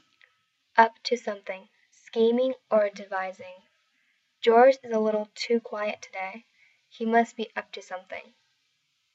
英語ネイティブによる発音 は下記のリンクをクリックしてください。